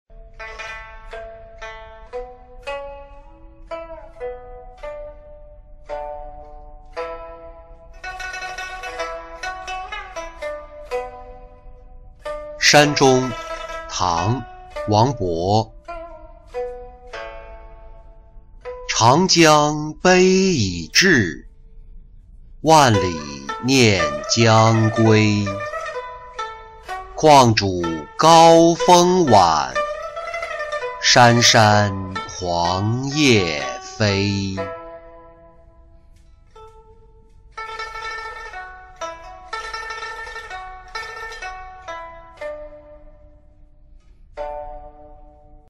登柳州峨山-音频朗读